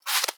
Sfx_creature_penguin_foot_slow_walk_01.ogg